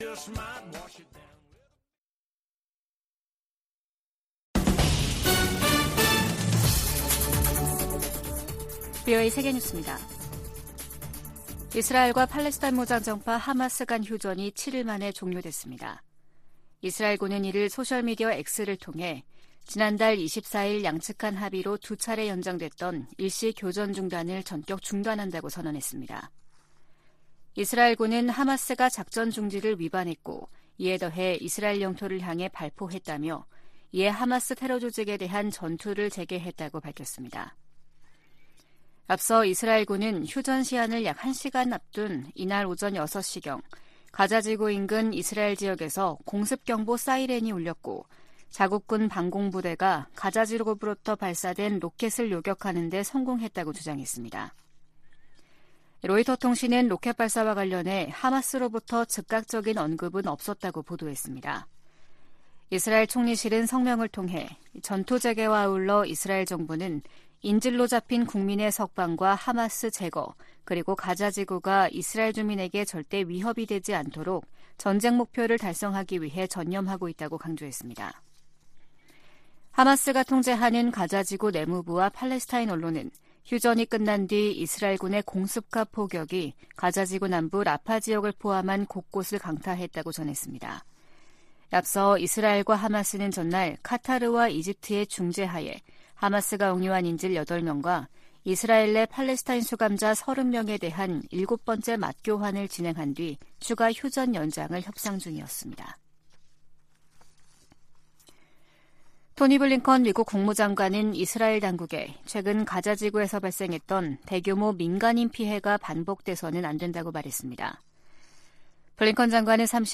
VOA 한국어 아침 뉴스 프로그램 '워싱턴 뉴스 광장' 2023년 12월 2일 방송입니다. 미국 정부가 북한의 군사 정찰위성 발사에 대한 대응 조치로 북한 국적자 8명과 기관 1곳을 전격 제재했습니다. 한국 정부가 북한의 정찰위성 개발 등에 관련한 북한 사람들에 독자 제재를 발표했습니다. 북한이 유엔 안보리를 선전 도구로 이용하고 있다고 유엔 주재 미국대표부가 비판했습니다.